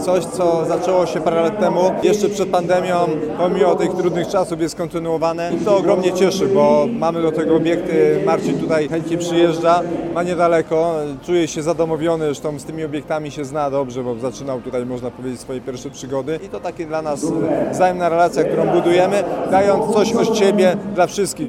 Prezydent miasta Przemysław Staniszewski mówił, że dla Gortata Zgierz jest wyjątkowym miejscem.